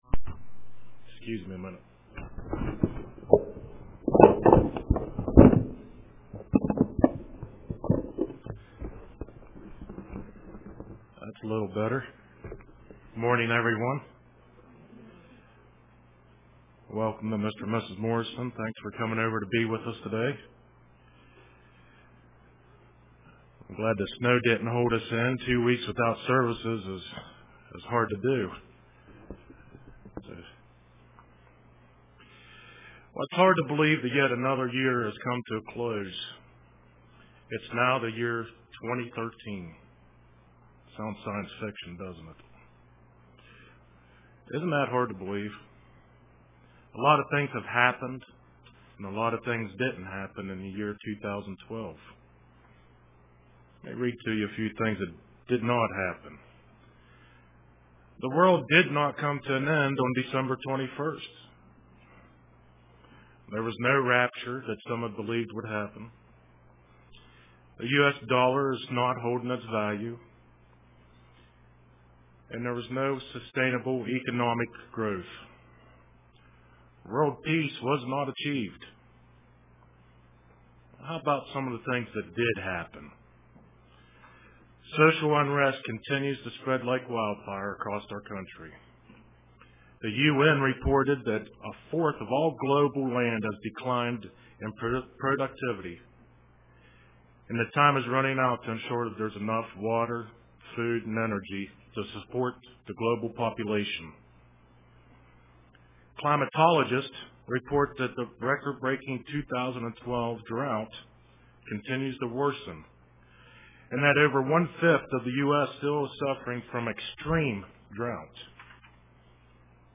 Print Fear the End UCG Sermon Studying the bible?